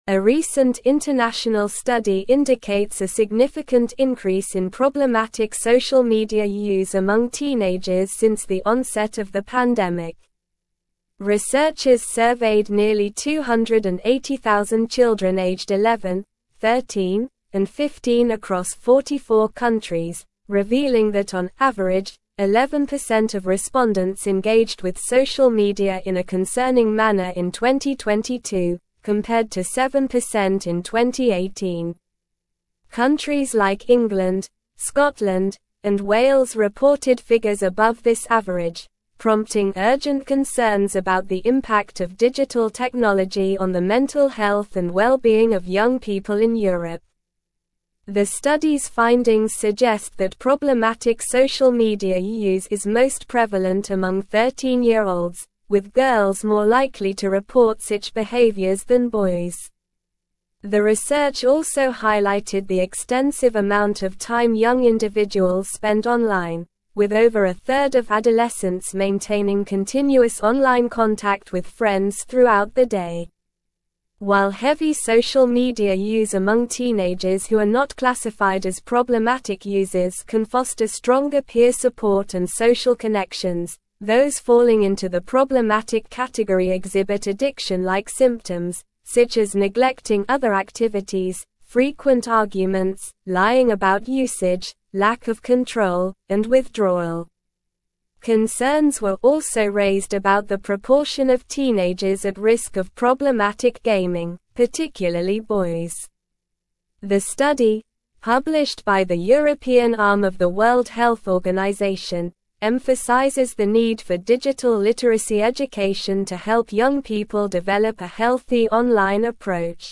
Slow
English-Newsroom-Advanced-SLOW-Reading-Concerning-Increase-in-Teenage-Social-Media-Use-Detected.mp3